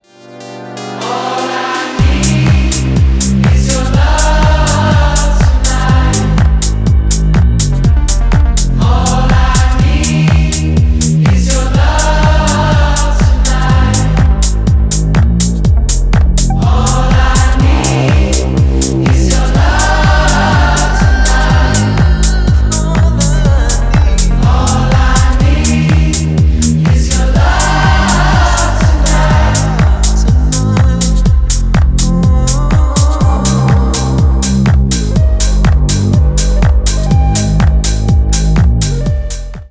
• Качество: 320, Stereo
спокойные
Tech House